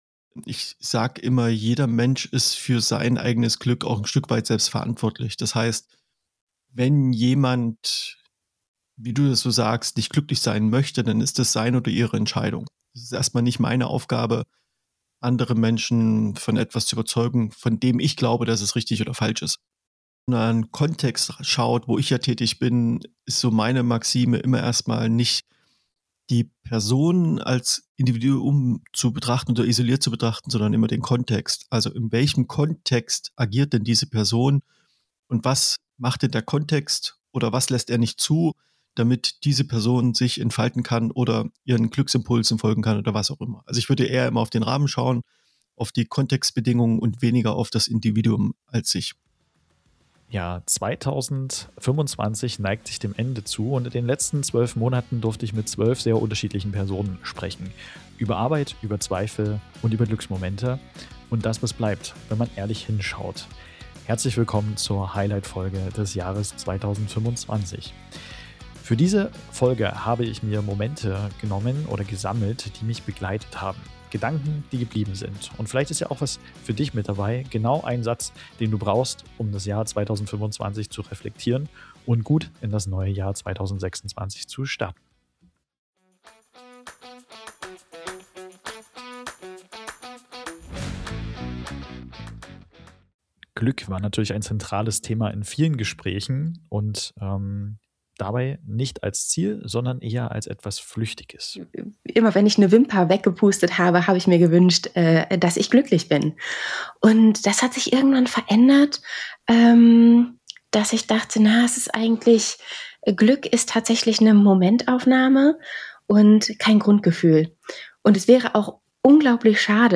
Zwölf Gespräche.
Hinweis zur Highlightfolge Diese Folge lebt von Pausen.